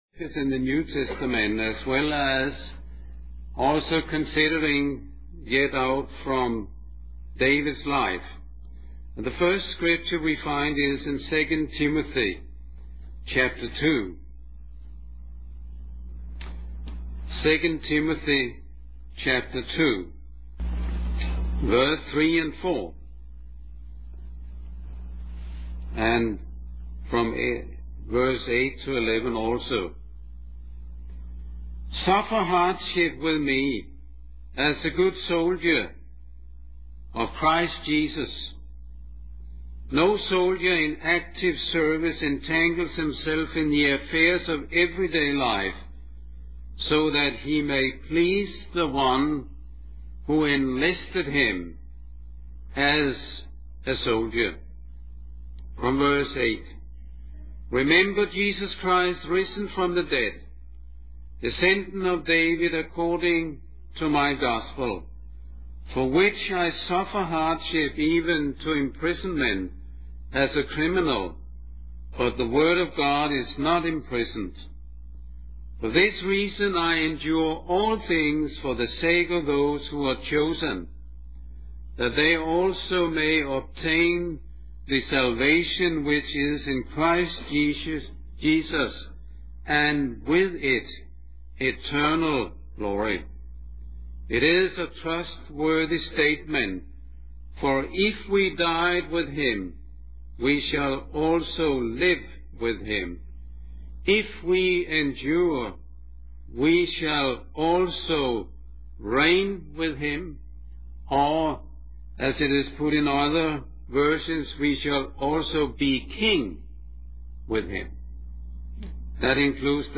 A collection of Christ focused messages published by the Christian Testimony Ministry in Richmond, VA.
Christian Family Conference